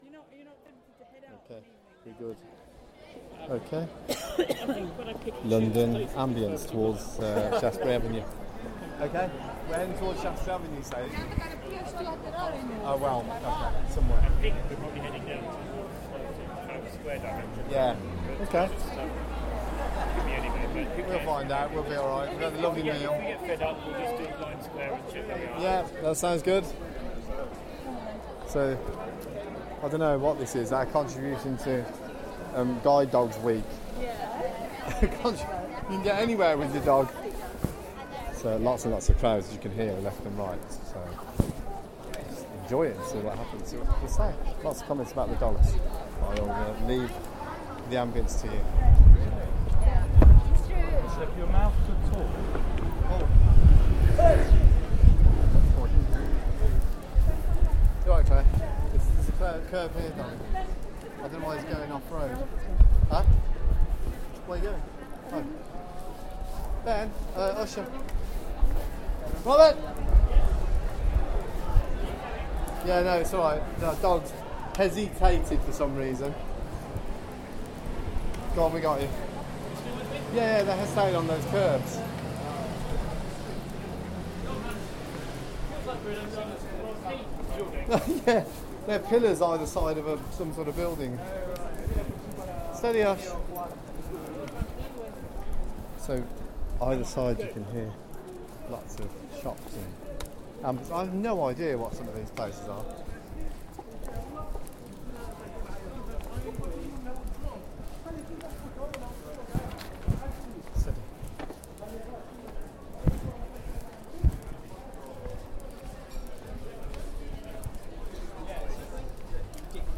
London Ambients: From somewhere around Soho to Embankment... Includes buskers who are actually quite good.